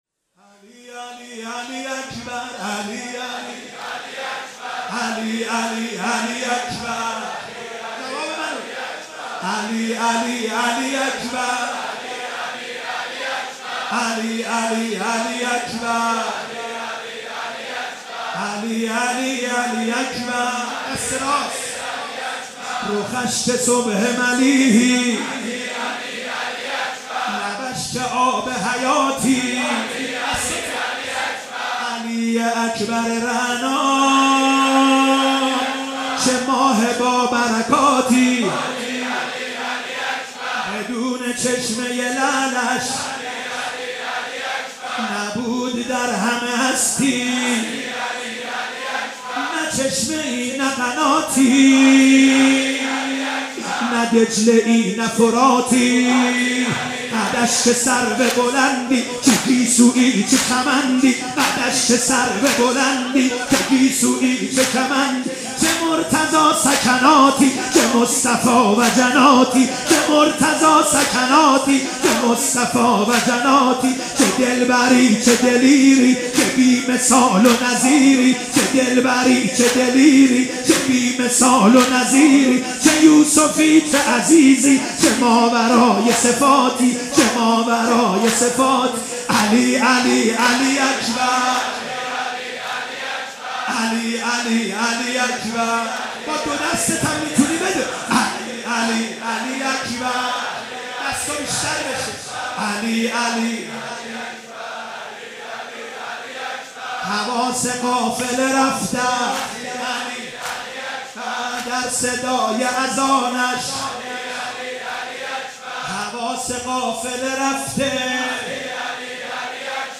رجز